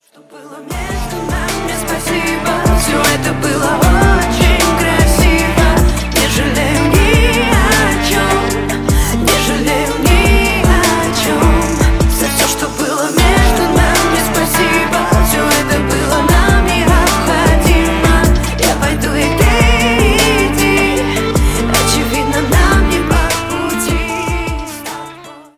• Качество: 128, Stereo
поп
женский вокал
грустные
спокойные